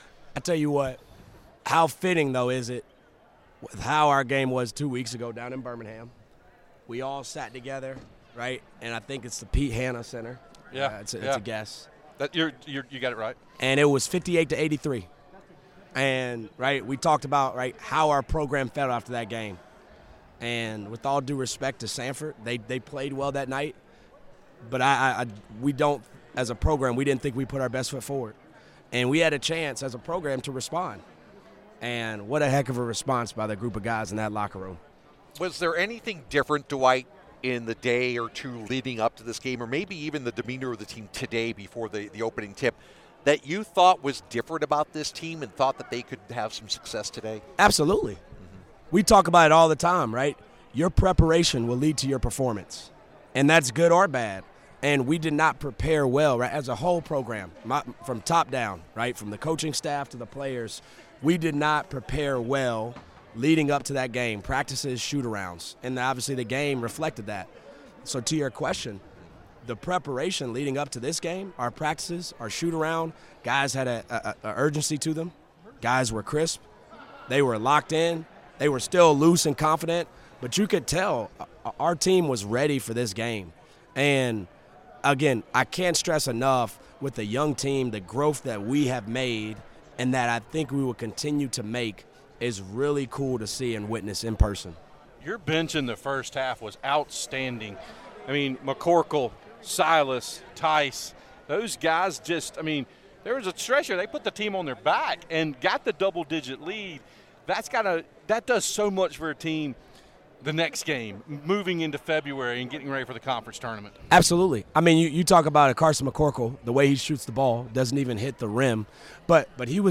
January 28, 2023 Download Listen Now All Categories Postgame Audio All Sports Men's Basketball Women's Basketball Loading More Podcasts...